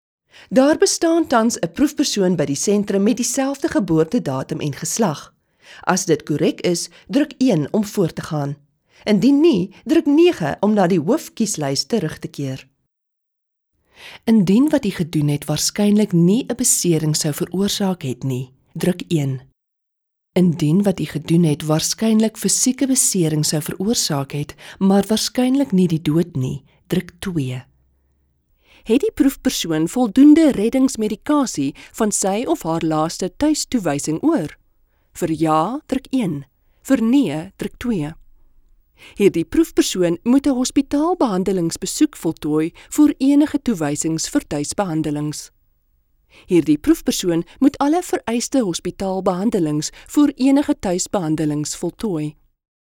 Female
Approachable, Authoritative, Confident, Conversational, Corporate, Gravitas, Natural, Reassuring, Warm
South African
My voice has been described as warm, earthy, sensual, clear and authoritative.